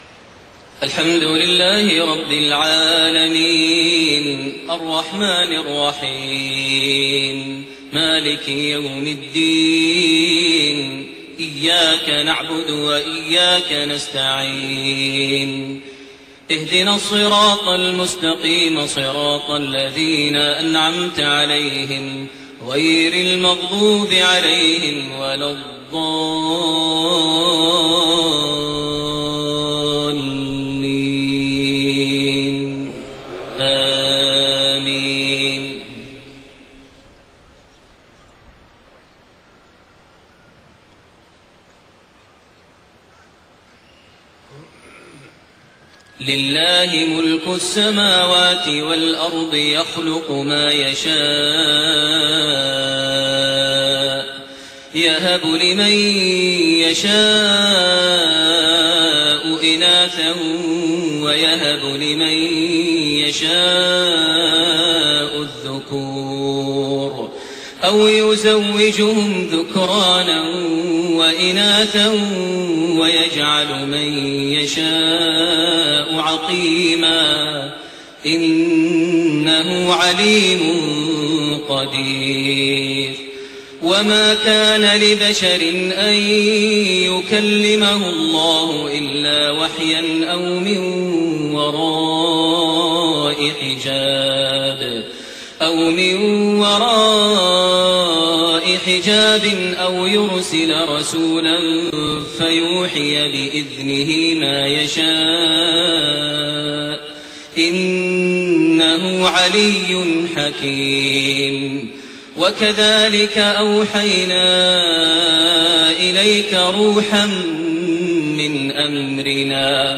Maghrib prayer from Surat Ash-Shura and Al-Munaafiqoon > 1430 H > Prayers - Maher Almuaiqly Recitations